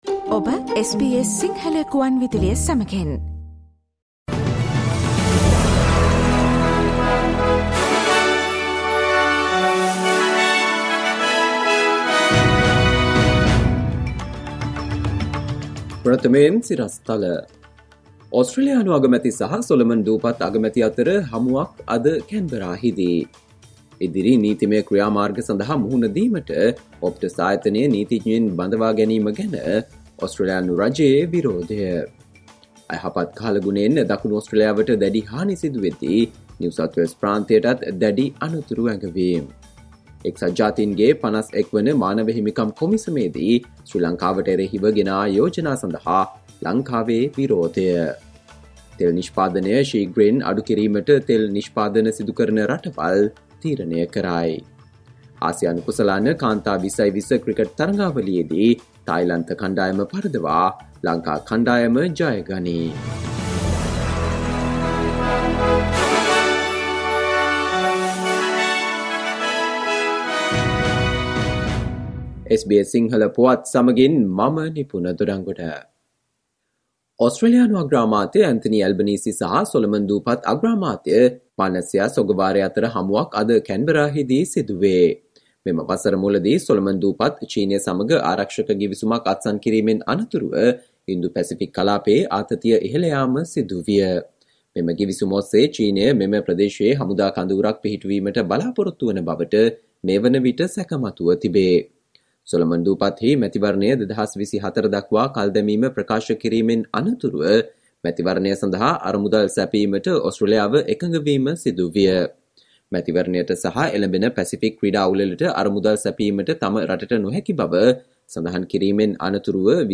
Listen to the SBS Sinhala Radio news bulletin on Friday 06 October 2022